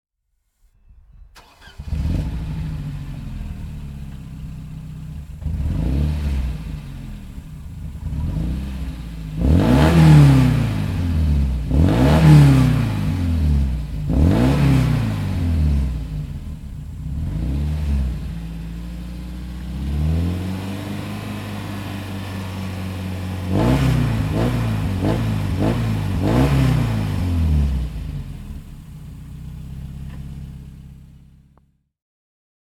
Mercedes-Benz 190 E 2.5-16 Evo 1 (1989) - Starten und Leerlauf
Merc_190E_Evo_1.mp3